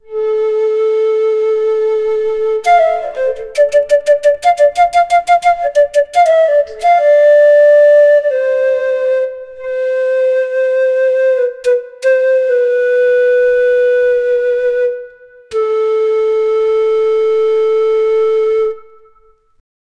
A melody of lord Krishna's flute....
a-melody-of-lord-krishnas-ndt7d6uc.wav